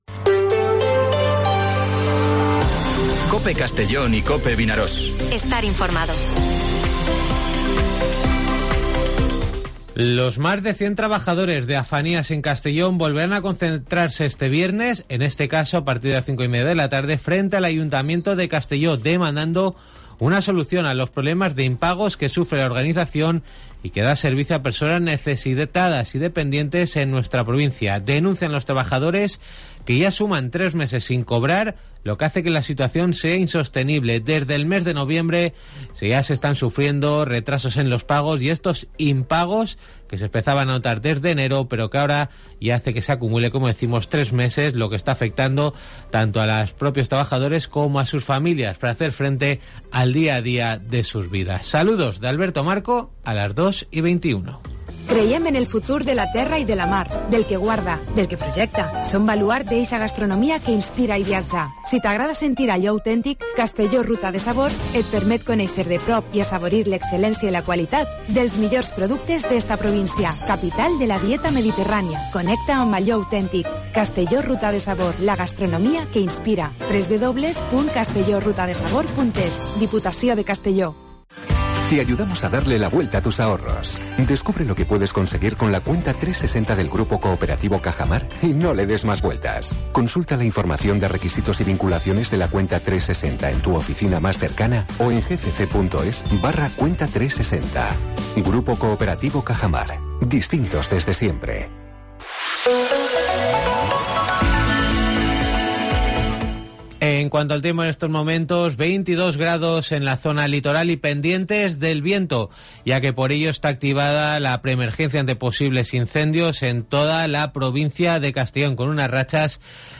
Informativo Mediodía COPE en Castellón (15/05/2023)